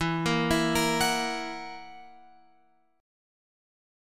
E Suspended 2nd Flat 5th